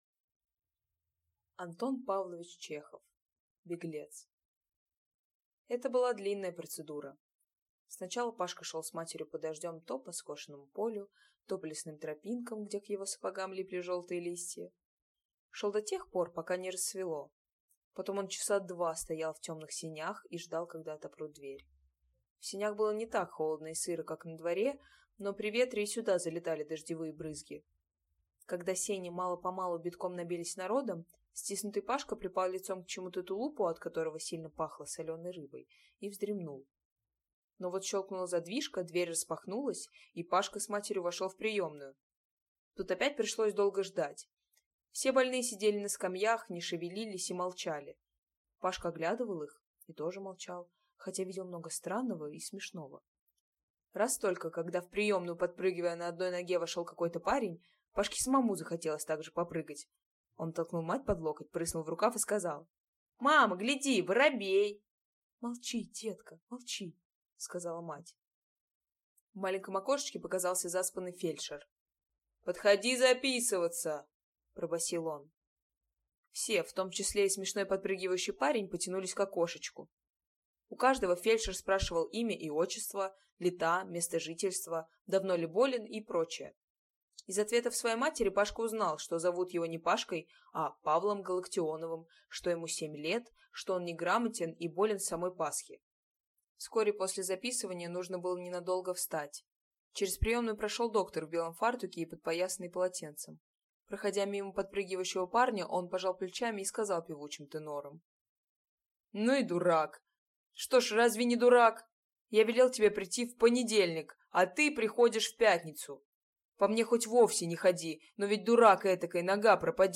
Аудиокнига Беглец | Библиотека аудиокниг